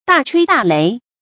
注音：ㄉㄚˋ ㄔㄨㄟ ㄉㄚˋ ㄌㄟˊ
大吹大擂的讀法